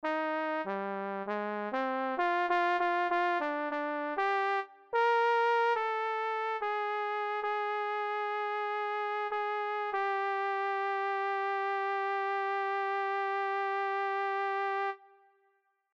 Key written in: C Minor
Type: Barbershop
Each recording below is single part only.